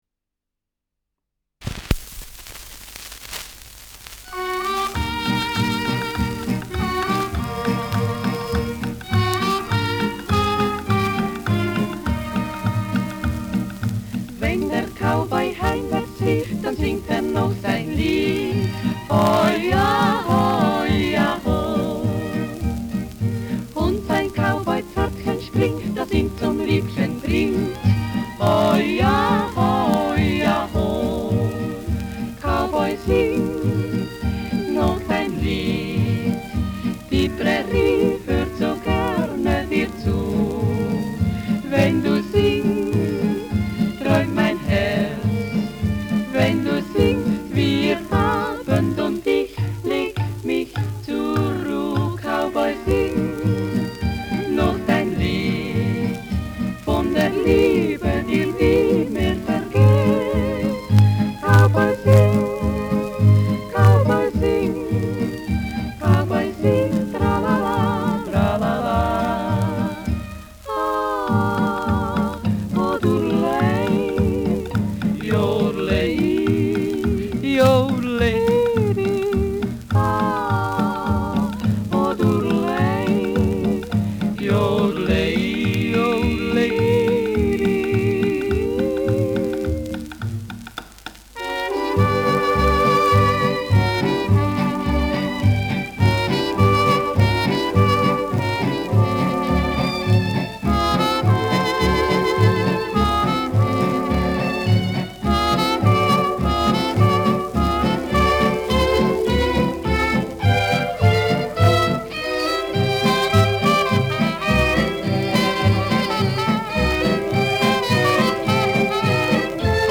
Schellackplatte
Jodlergruppe* FVS-00014